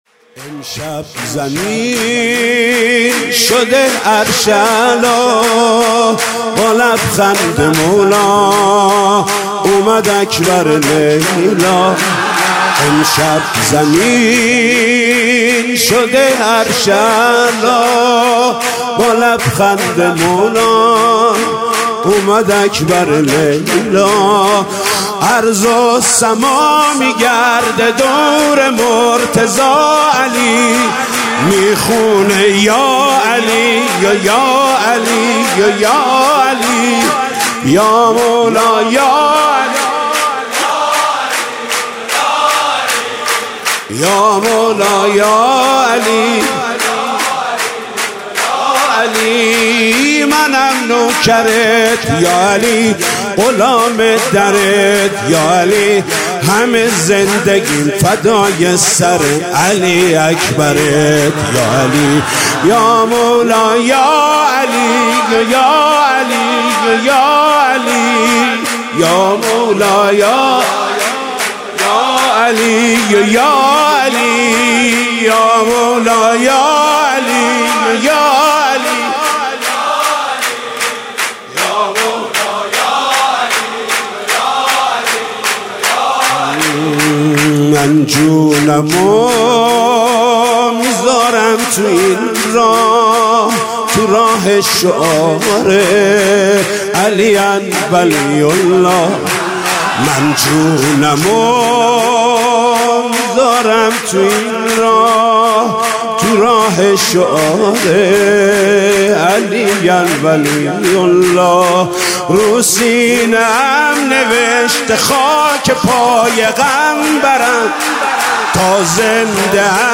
سرودخوانی